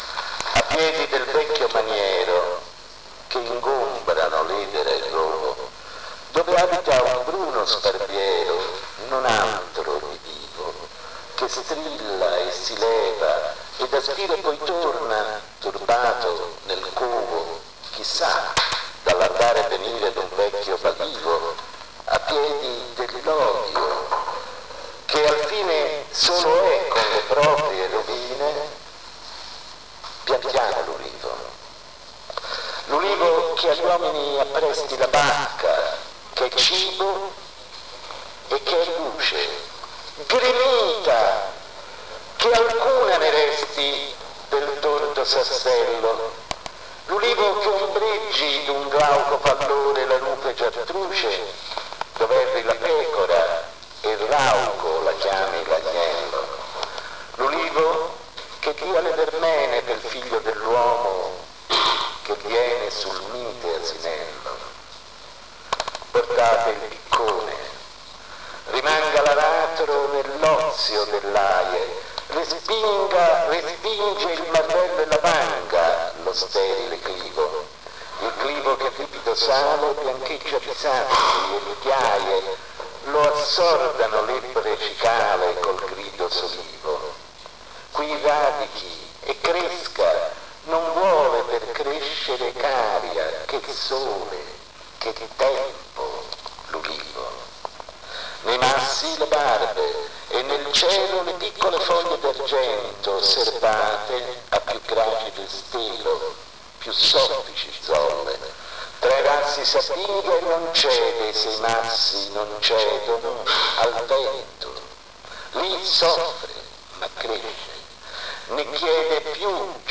G.PASCOLI CANTI DI CASTELVECCHIO LA CANZONE DELL'OLIVO COMMENTO . LETTURA